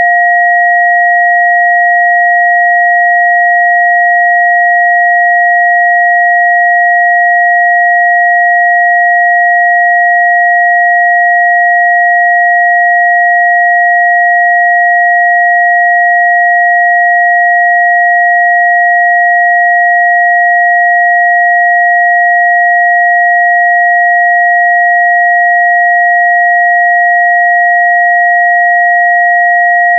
Two Tone Audio file for GNU Radio Transceivers
Two_Tone_Test.wav